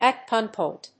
アクセントat gúnpoint